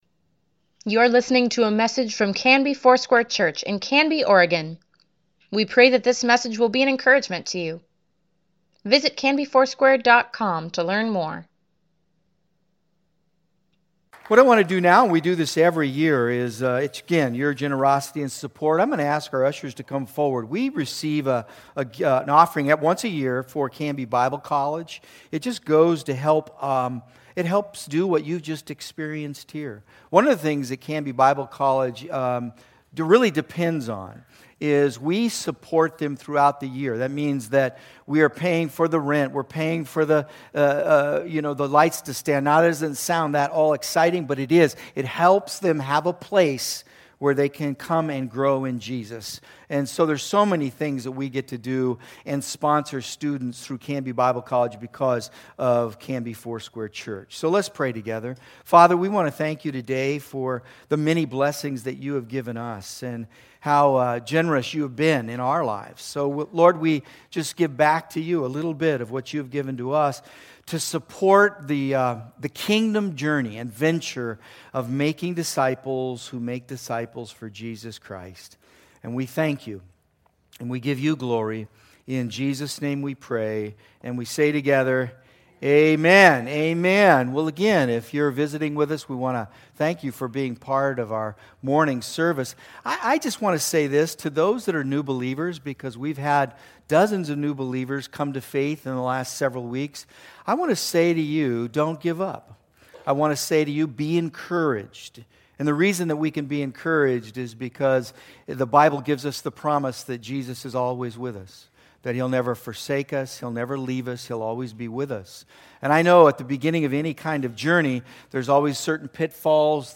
Weekly Email Water Baptism Prayer Events Sermons Give Care for Carus Risen: Life After the Resurrection (2) April 22, 2018